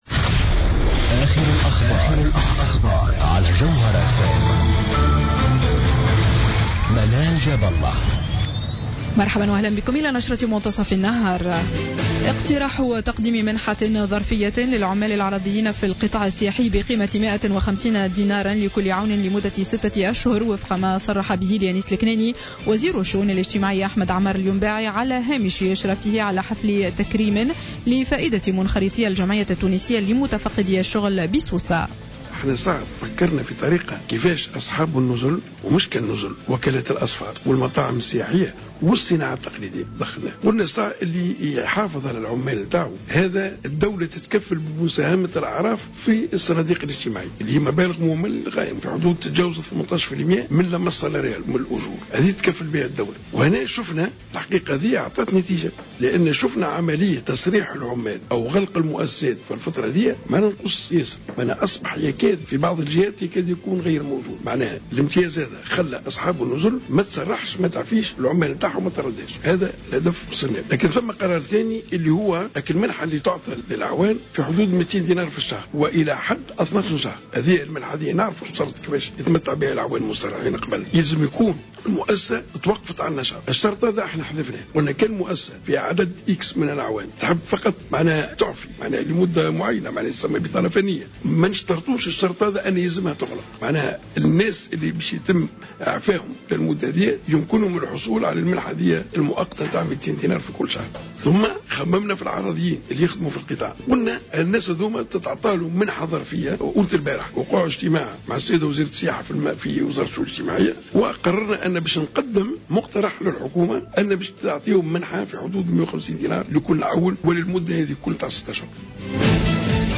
نشرة أخبار منتصف النهار ليوم السبت 29 أوت 2015